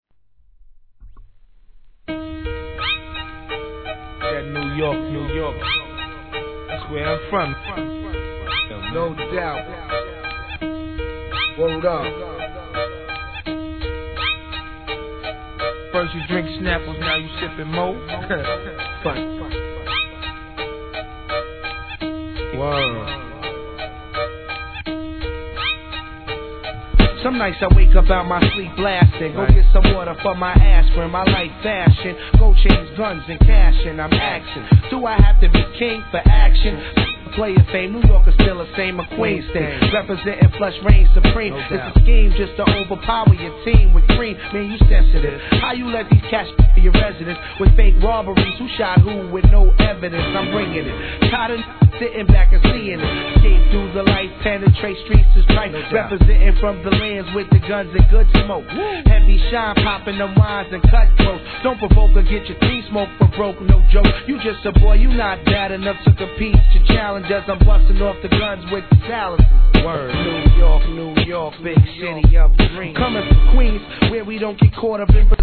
HIP HOP/R&B
歯がゆくなる様な鳴り物のイントロが癖になります！